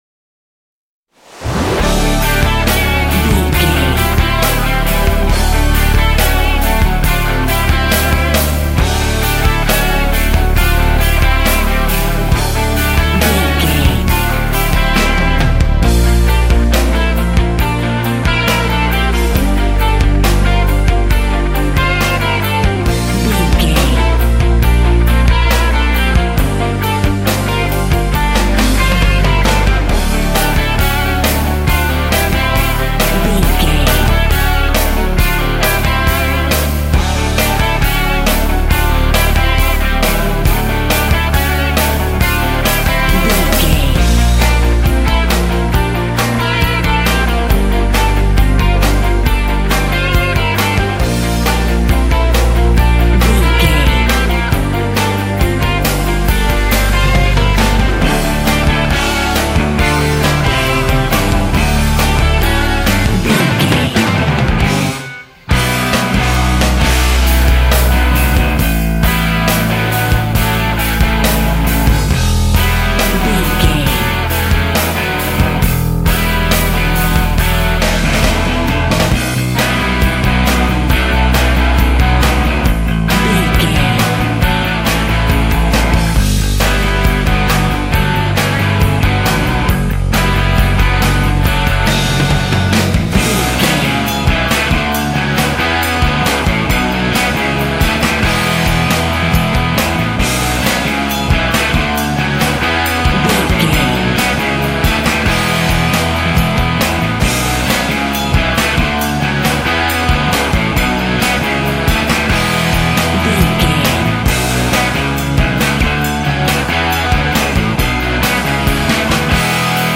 Mixolydian
electric guitar
drums
bass guitar